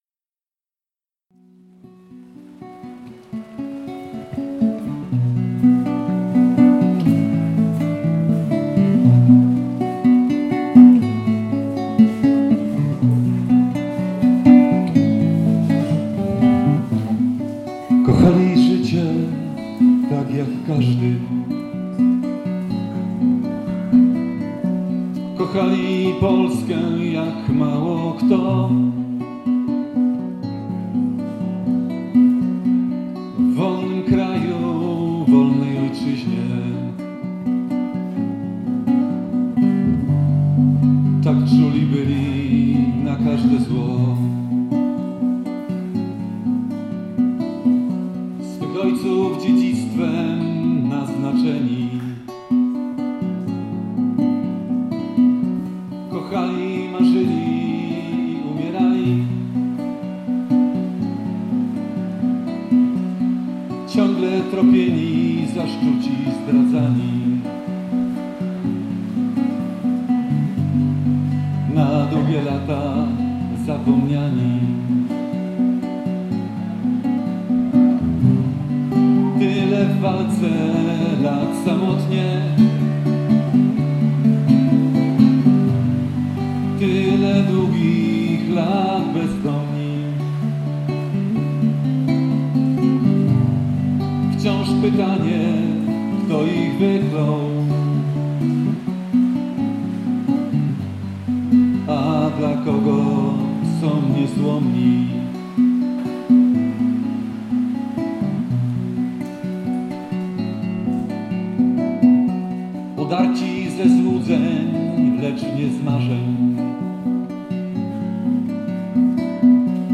W leśnym sanktuarium o Żołnierzach "Wyklętych"
Oto dalszy ciąg relacji z uroczystości po Biegu "Wilczym Tropem" w leśnym sanktuarium w Berrima Penrose Park. W leśnej ciszy, zakłócanej jedynie pluskiem deszczu